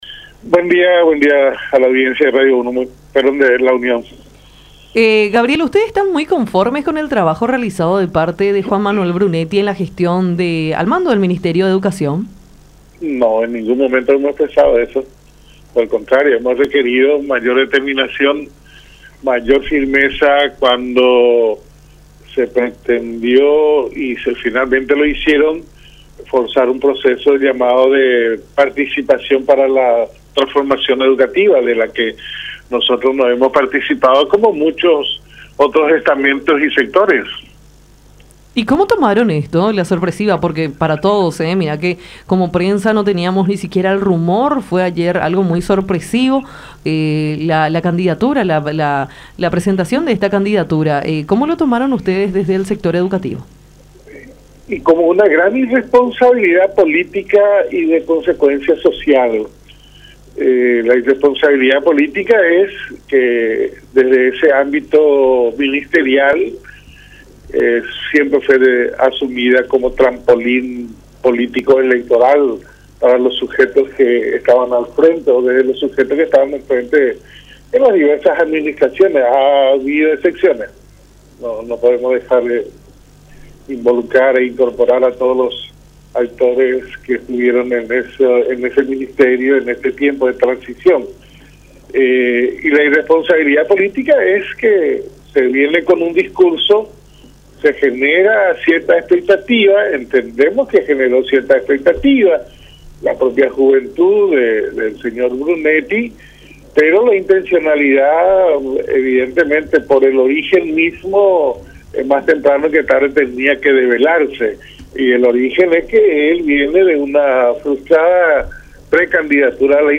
en contacto con Nuestra Mañana por La Unión